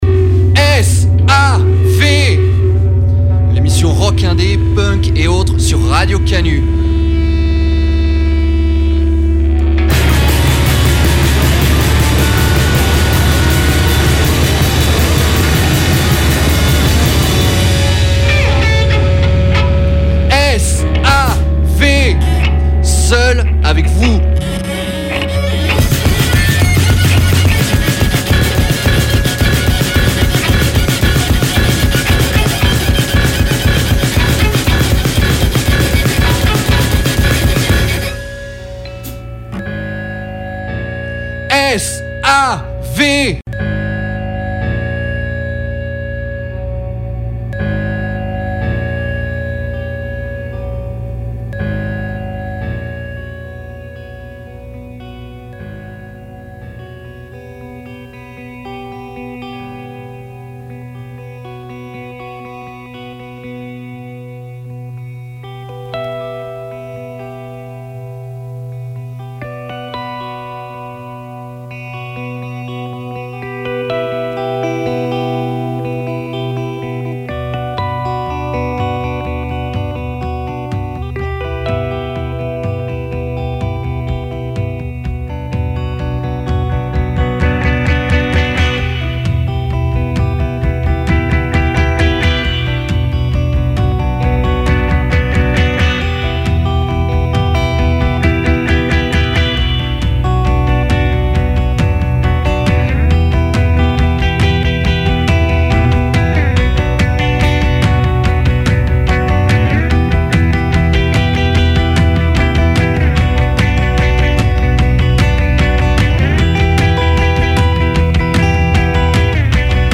des enregistrements live de 1977 et 2009